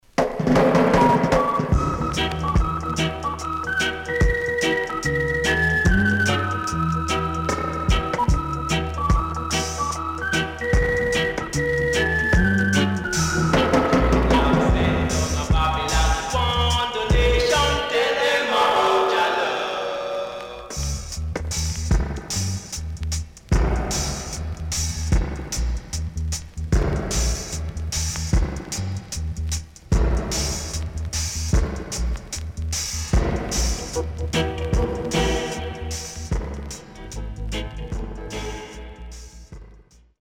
HOME > Back Order [VINTAGE 7inch]  >  KILLER & DEEP
CONDITION SIDE A:VG(OK)
SIDE A:所々チリノイズがあり、少しプチノイズ入ります。